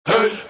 SouthSide Chant (11).wav